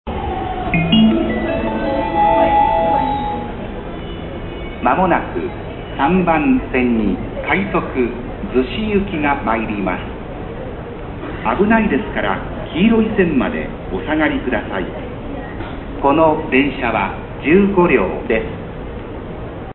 接近放送逗子行き15両
B線の接近放送です。
15両時の案内です。「○○/寄りには/止まりません」が無いのが特徴です。